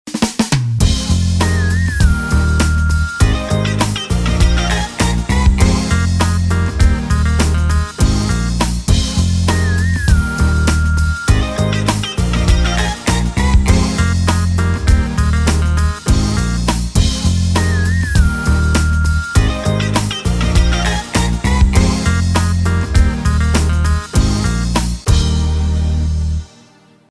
-Tempo Cool